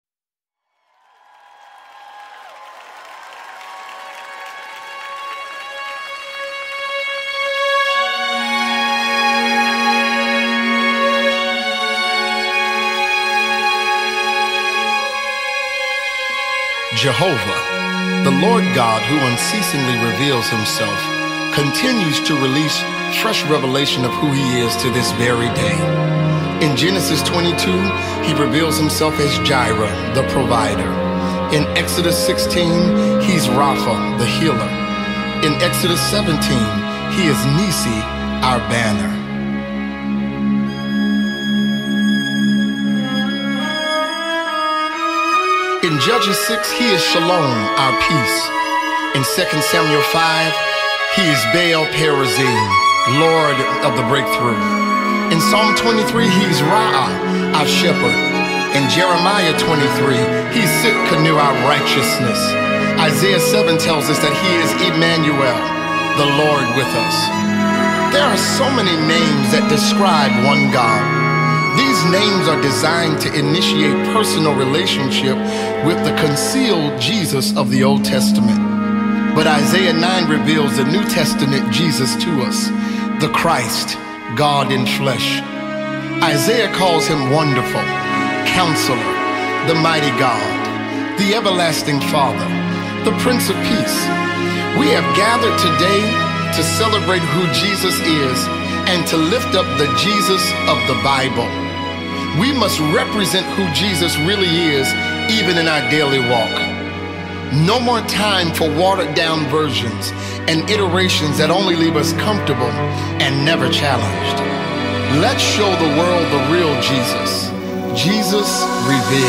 The multitalented American gospel singer
spirit-filled song